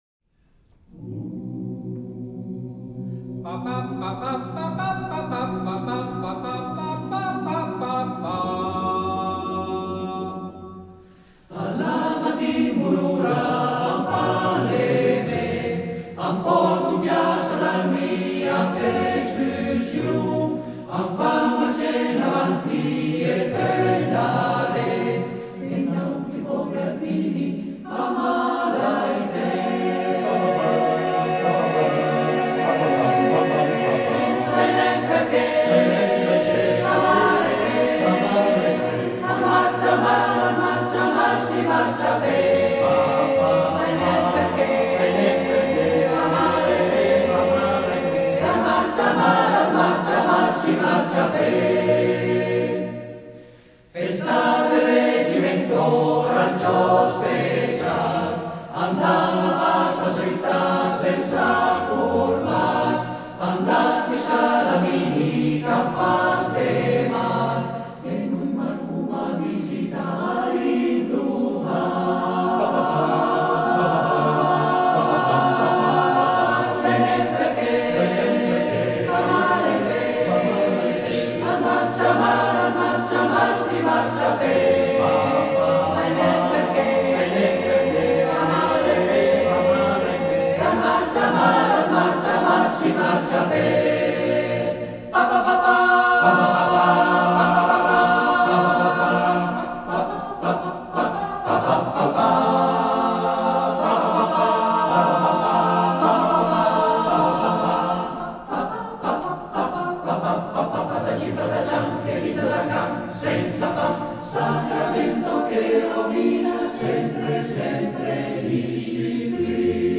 Esecutore: Coro Edelweiss